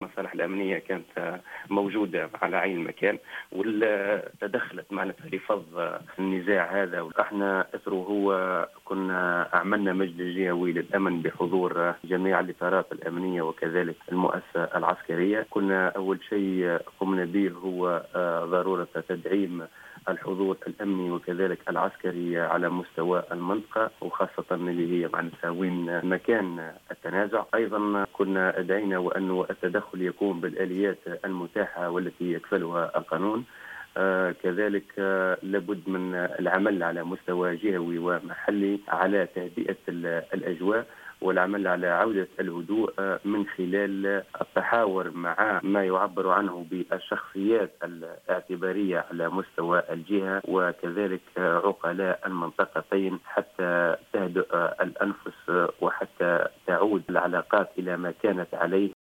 Dans une déclaration accordée à Jawhara FM ce samedi 10 juin 2017, le gouverneur de Kébili Sami Ghabi a insisté sur la nécessité de calmer le jeu entre les habitants de Bechni et de Jersine, dans le gouvernorat de Kébili.